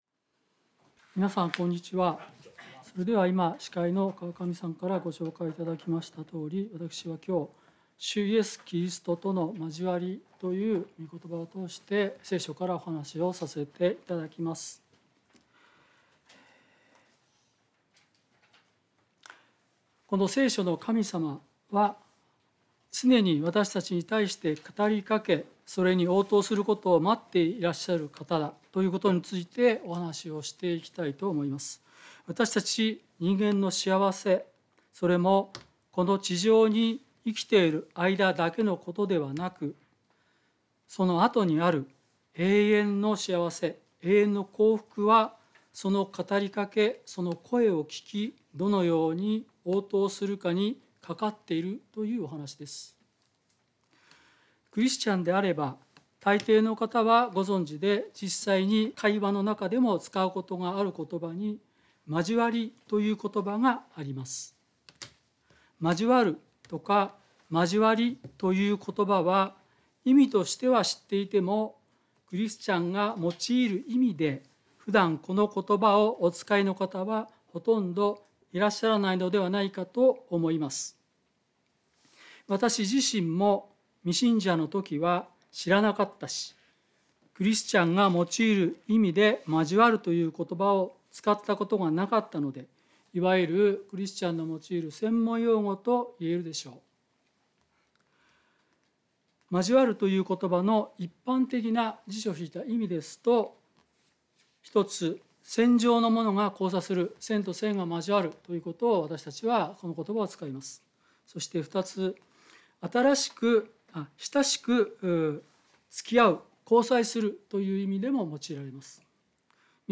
聖書メッセージ No.251